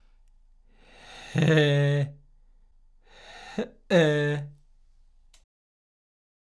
※よくある「息漏れが消えてしまったNGパターン」２種類